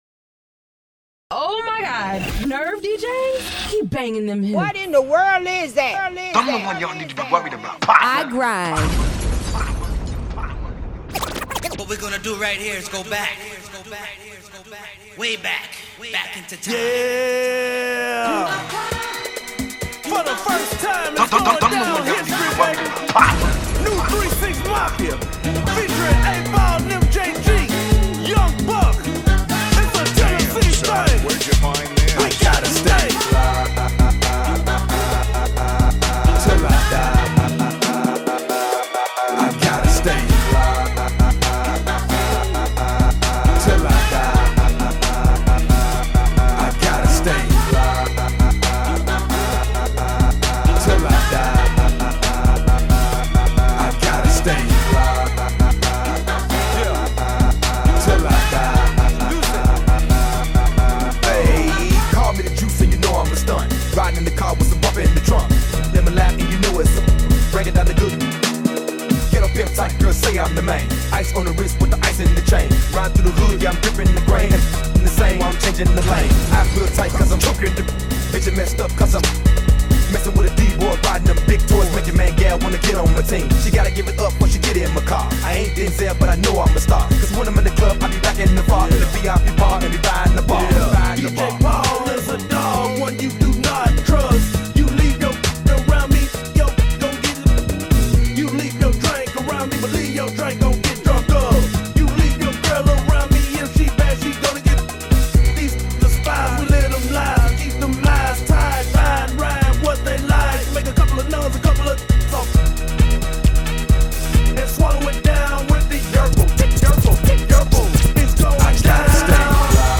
DJ Mixes
Dope collection of some 2000s hits!